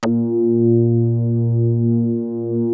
P.5 A#3.8.wav